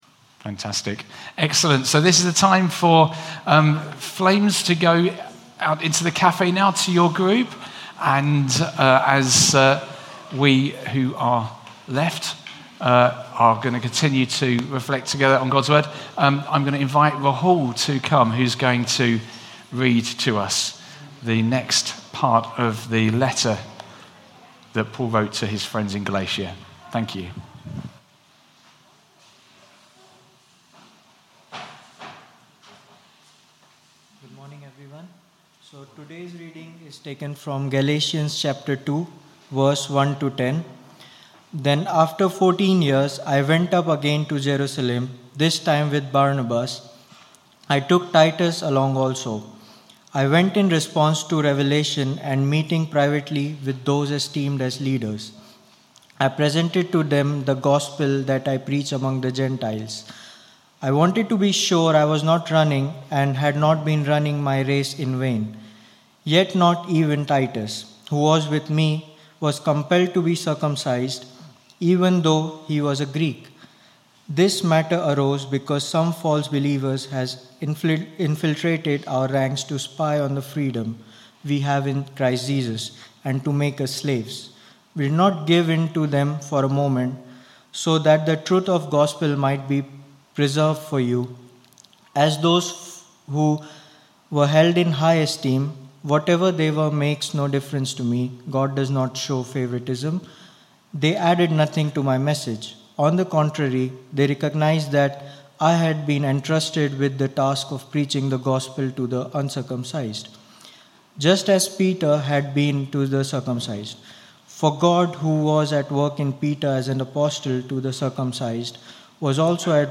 Sermon 2nd July 2023 11am gathering
We have recorded our talk in case you missed it or want to listen again.
Family Gathering for Worship 11am 2nd July 2023